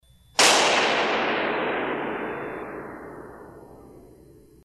Balloon3.mp3